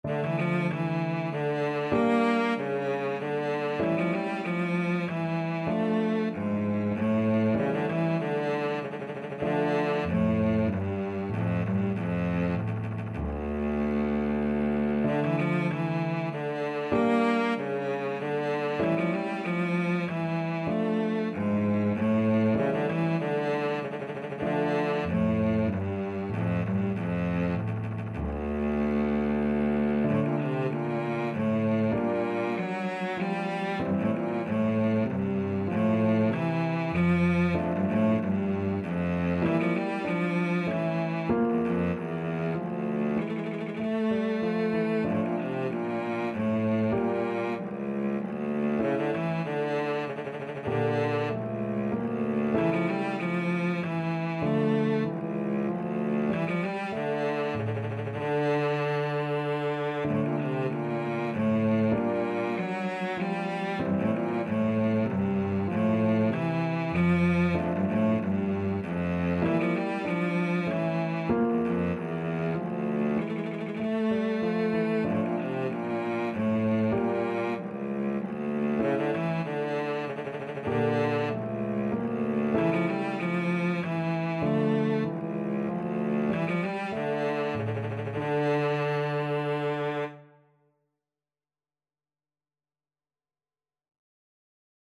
Cello
D minor (Sounding Pitch) (View more D minor Music for Cello )
3/8 (View more 3/8 Music)
Classical (View more Classical Cello Music)
scarlatti_k23_sonata_VLC.mp3